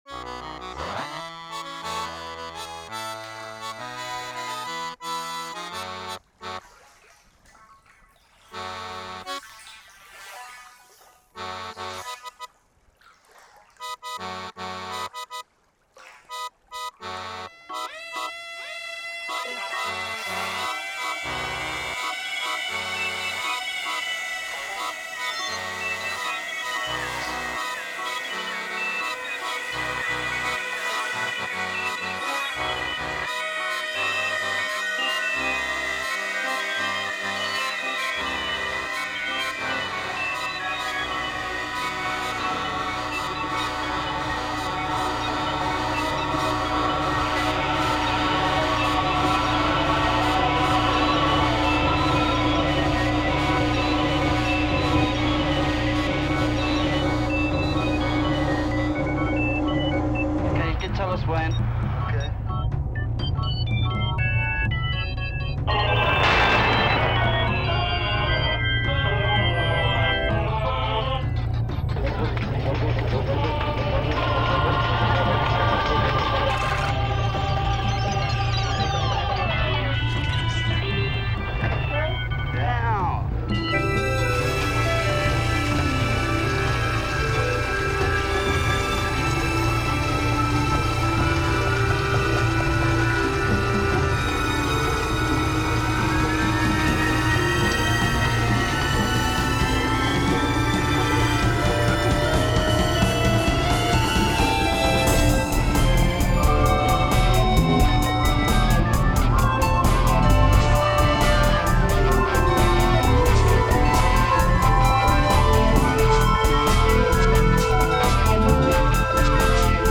Between pop and modern classic